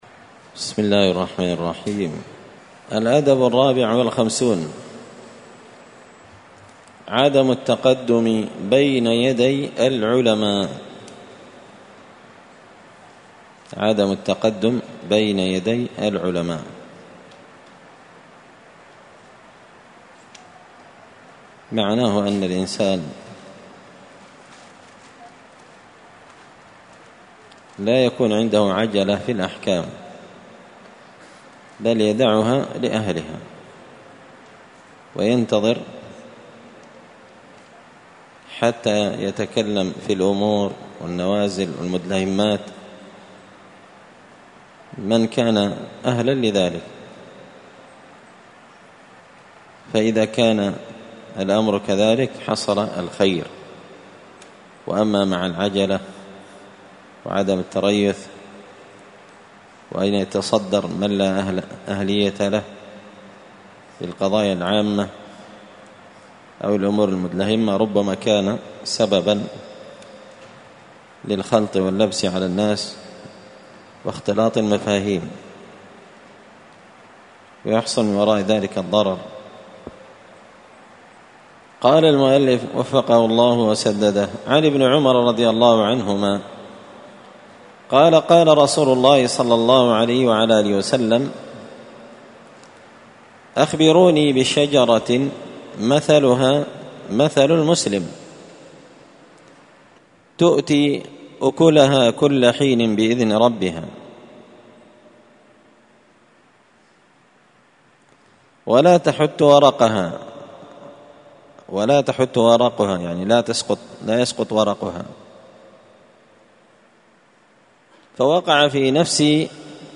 الدرس الثاني والستون (62) الأدب الرابع والخمسون عدم التقدم بين يدي العلماء